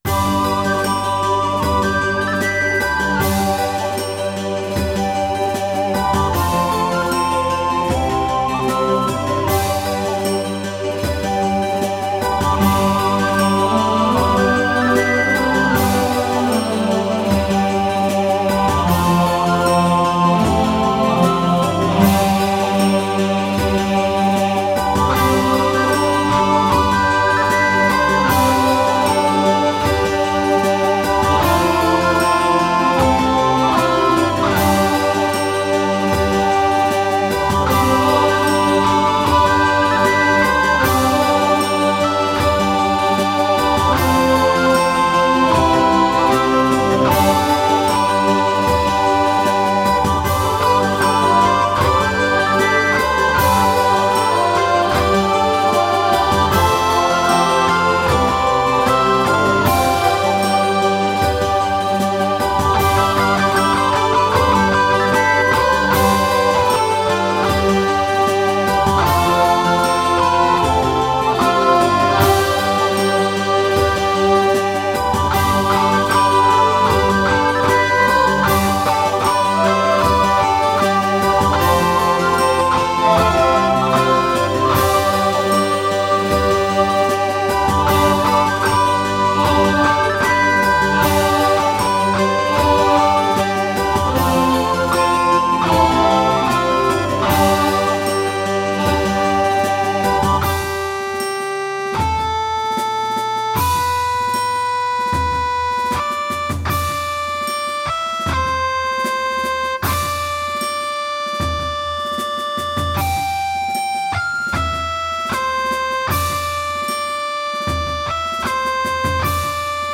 Tempo: 77 bpm / Datum: 19.03.2017